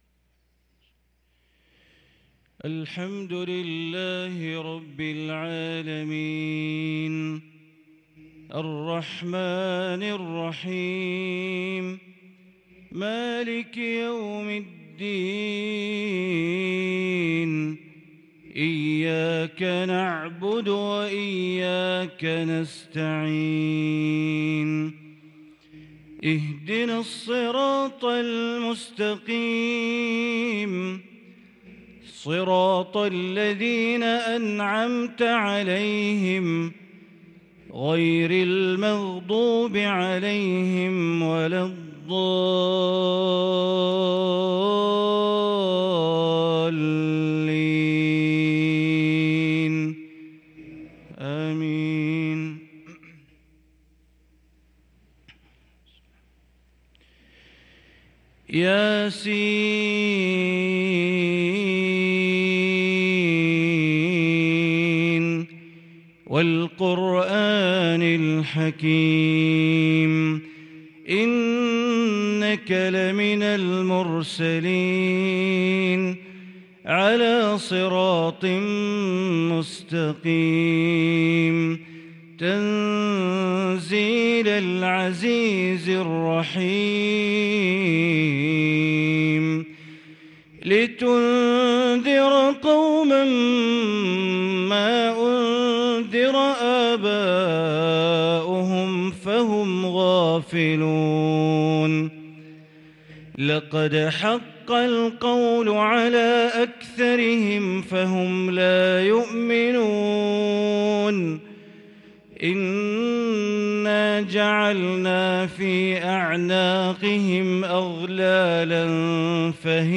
صلاة الفجر للقارئ بندر بليلة 8 ربيع الأول 1444 هـ